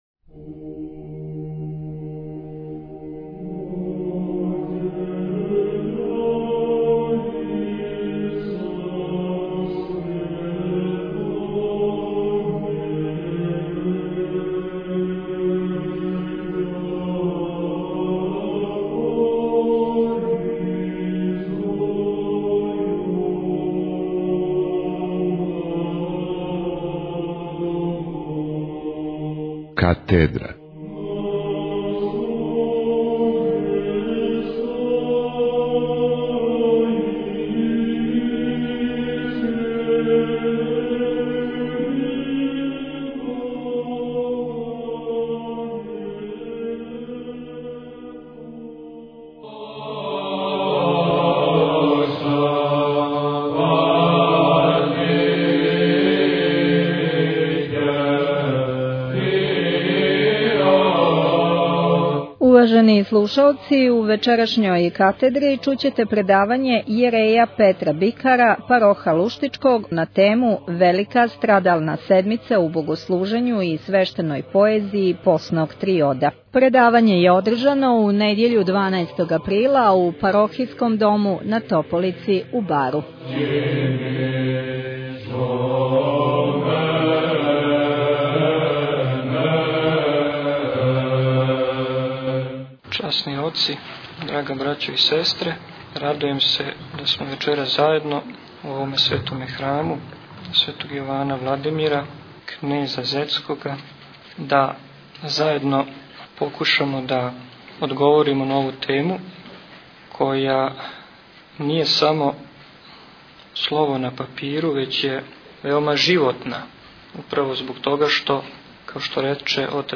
Предавање
Предавање је одржано у Парохијском дому на Тополици у Бару у недјељу 12. априла 2009. године.